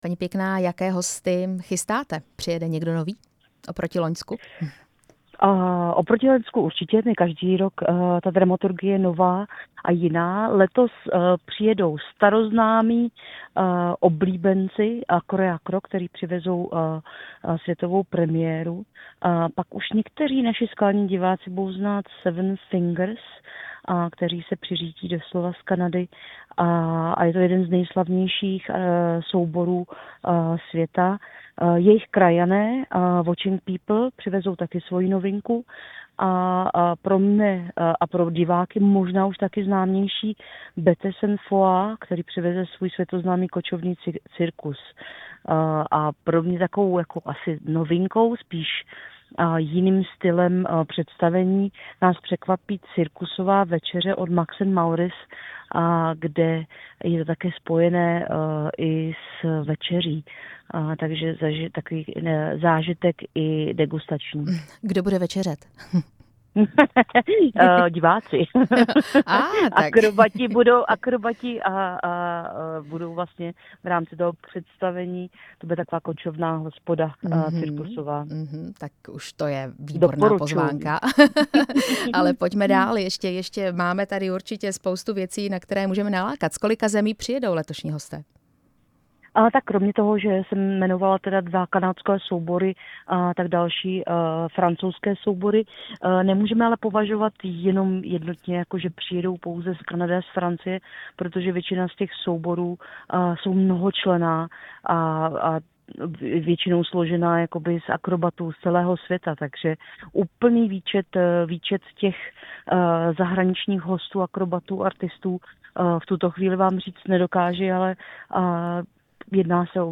Rozhovor